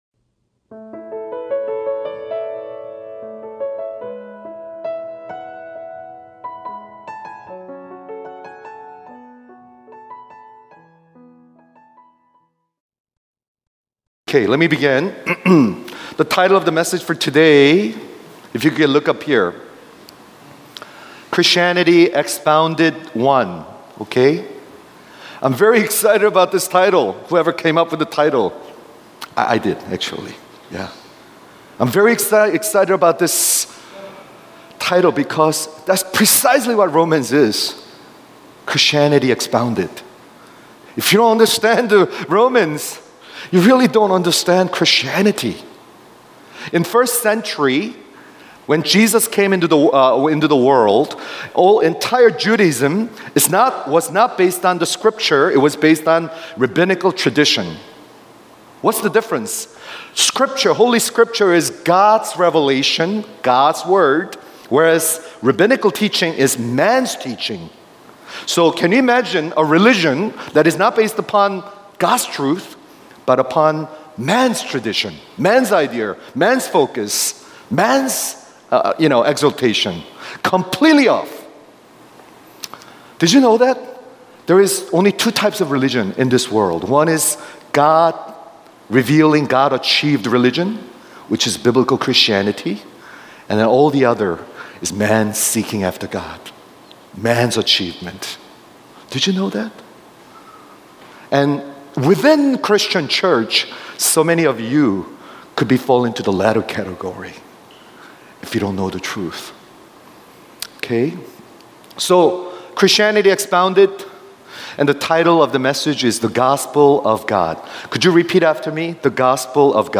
Christianity Expounded (1) – Preaching Series from the Book of Romans – The Gospel of God | Romans 1:1-17